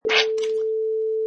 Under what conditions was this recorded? Product Info: 48k 24bit Stereo Try preview above (pink tone added for copyright).